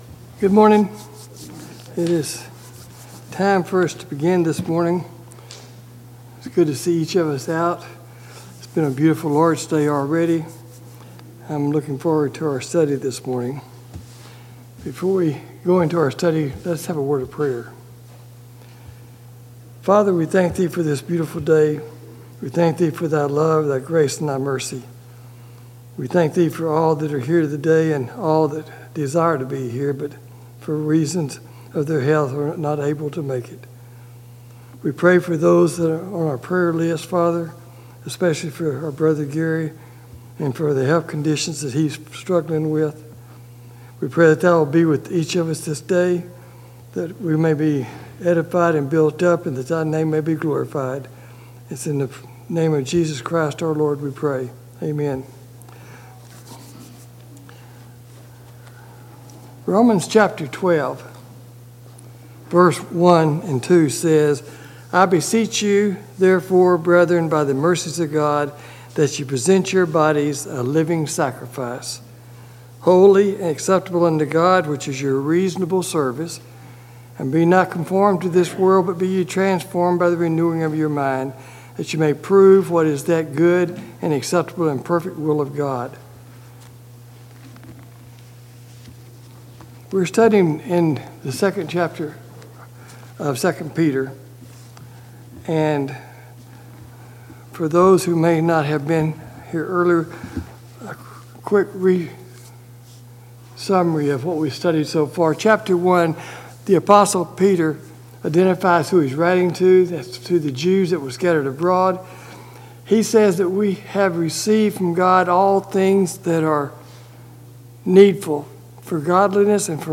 Bible Study: II Peter 2:17 – 22
Service Type: Sunday Morning Bible Class